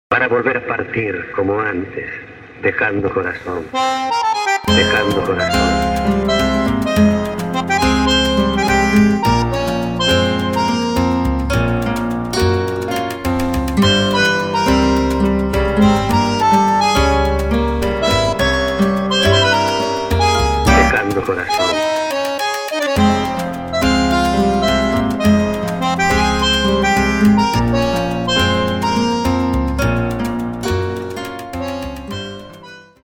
Moderne Tangos/ Tango-Atmosphäre/ Non-Tango